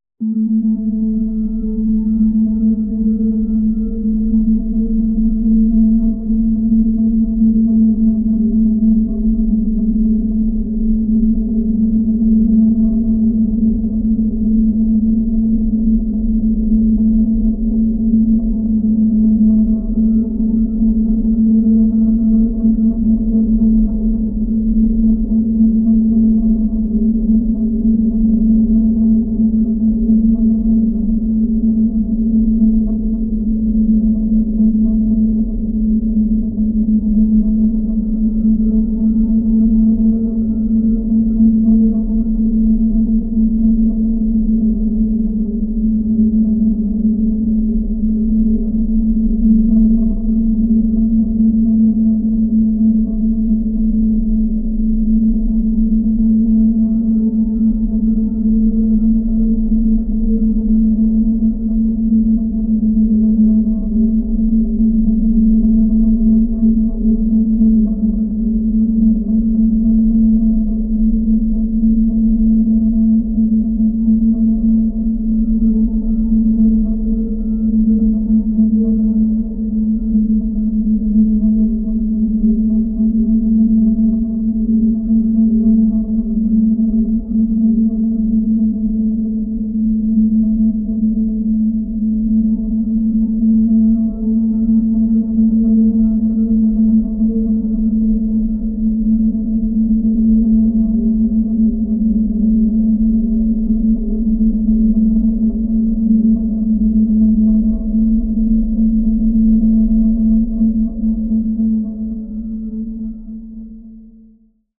Horror & Ambiance